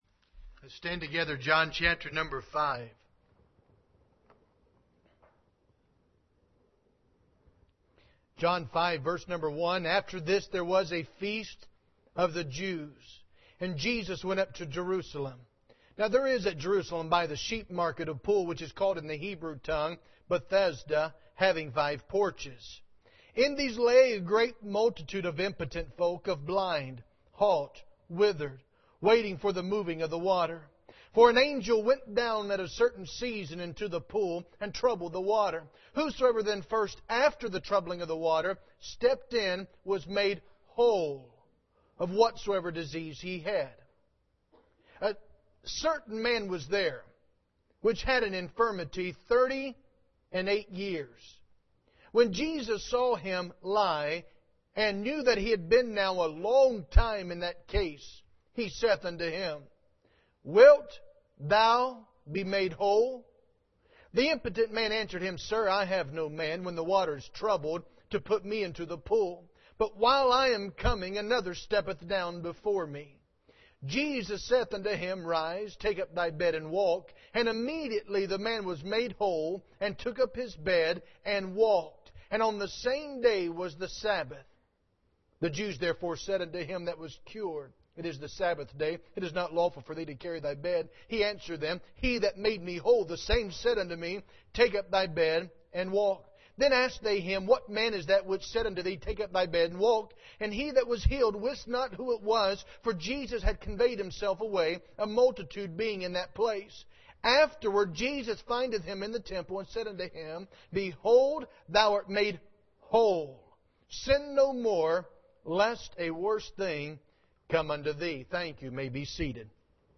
Passage: John 5:1-14 Service Type: Revival Service